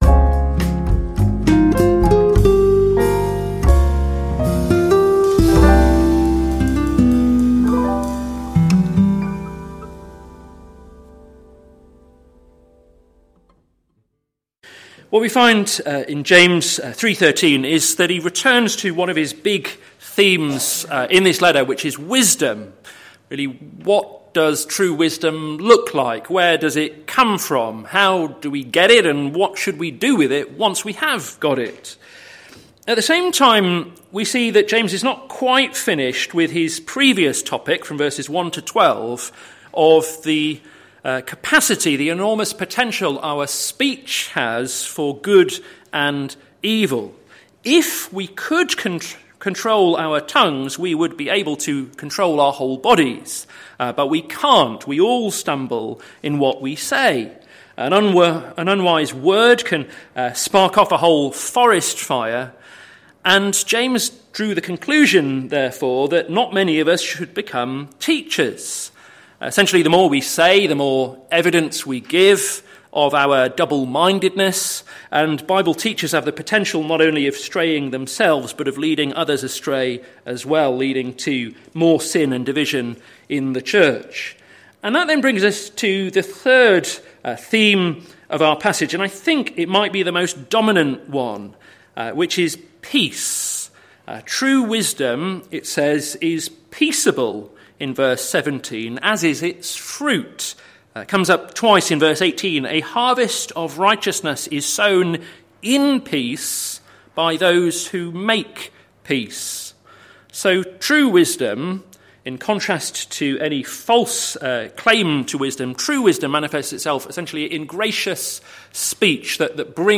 Sermon Series - Caught in Two Minds - plfc (Pound Lane Free Church, Isleham, Cambridgeshire)